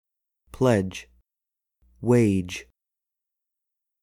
今回ご紹介するのは、2016年12月29日に配信した英語ニュース（ネイティブ音声付き版）です。
Step1:ディクテーション